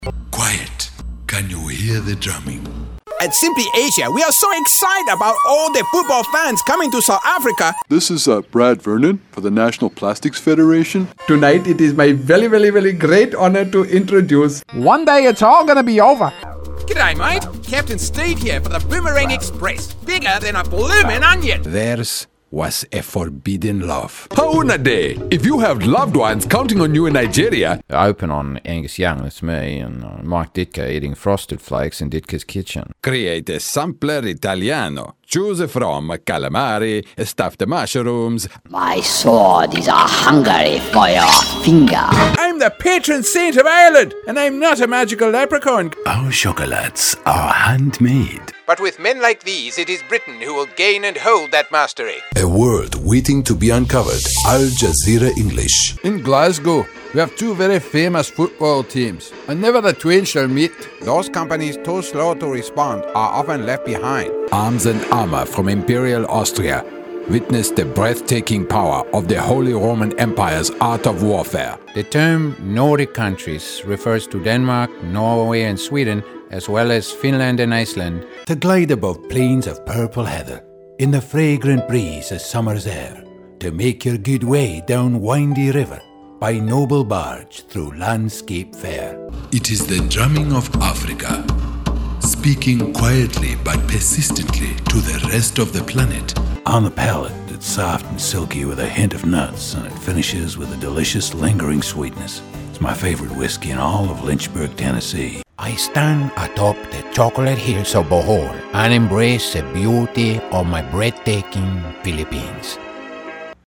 Rich, Distinctive, International, South African, Pan-African, Voice-over , Accents, Mid-Atlantic
Sprechprobe: Sonstiges (Muttersprache):